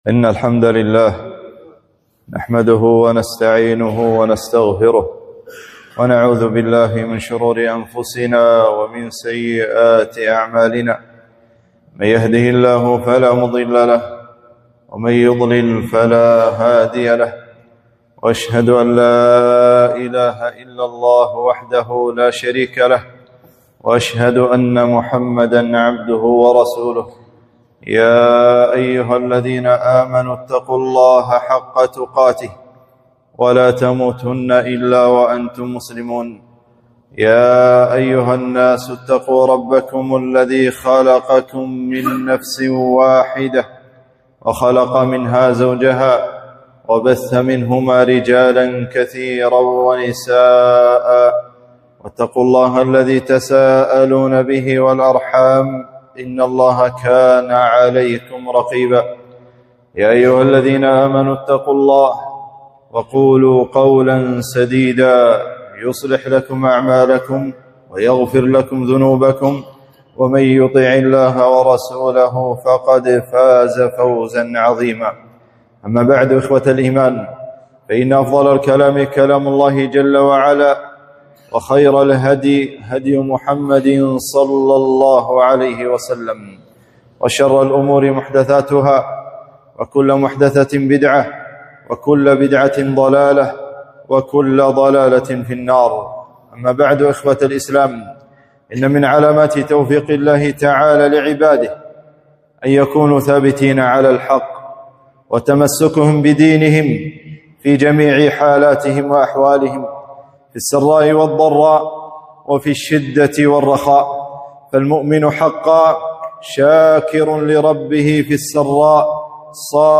خطبة - فالله خير حافظا